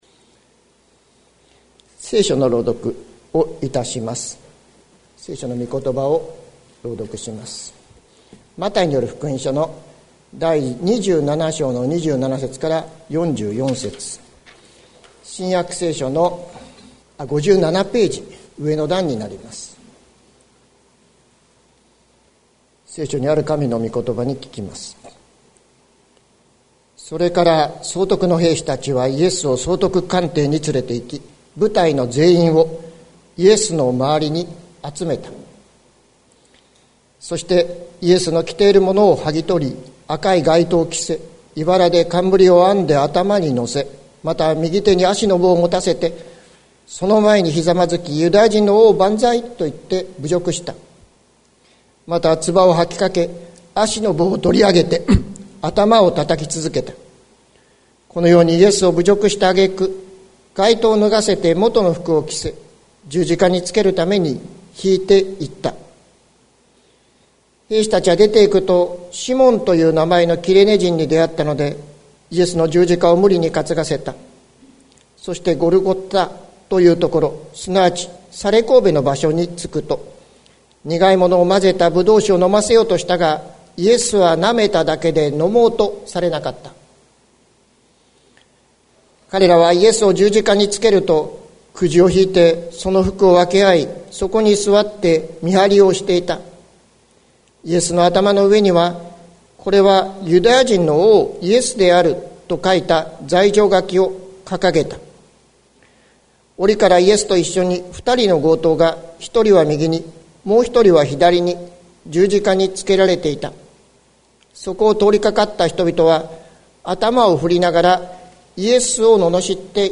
2021年03月07日朝の礼拝「この方にこそ救いがある」関キリスト教会
説教アーカイブ。